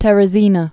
(tĕrĭ-zēnə)